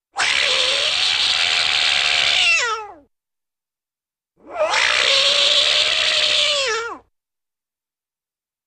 Cat Yowls - 2 Types ( I.e. Step On Cat's Tail ).